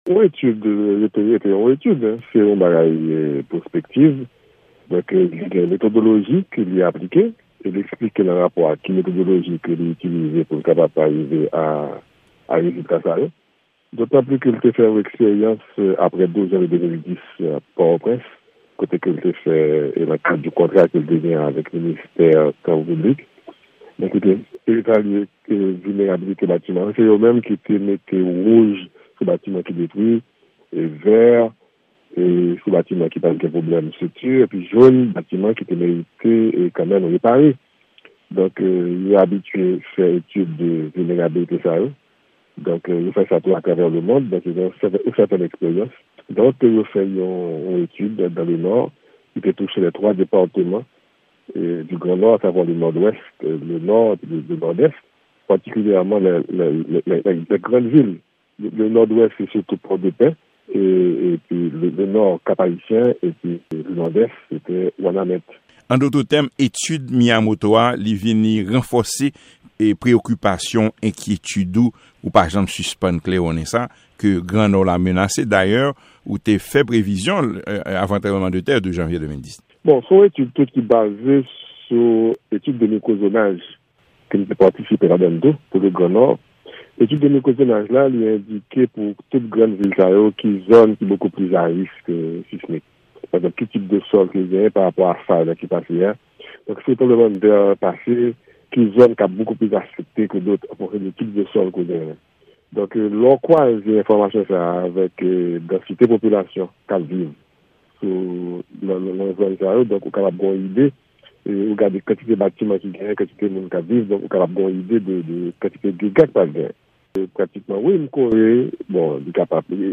Deklarasyon Direktè Biwo Min peyi Dayiti, Enjenyè Claude Prépetit